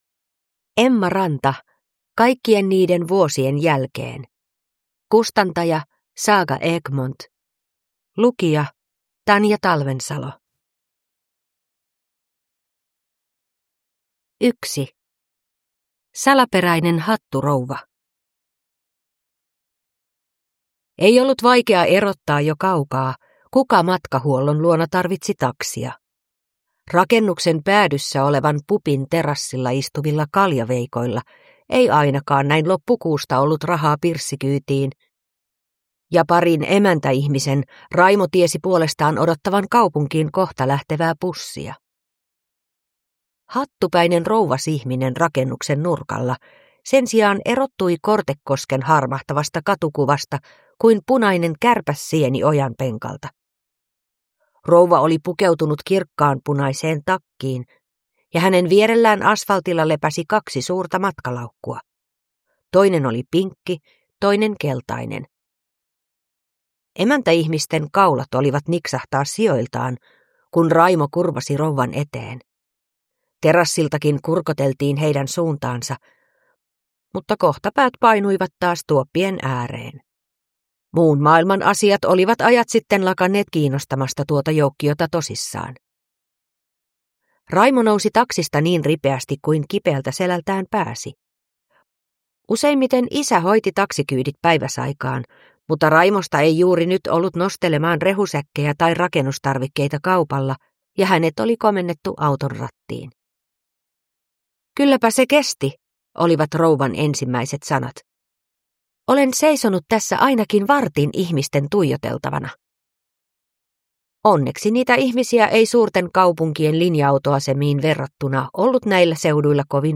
Kaikkien niiden vuosien jälkeen – Ljudbok